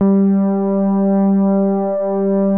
MOOG #9  G4.wav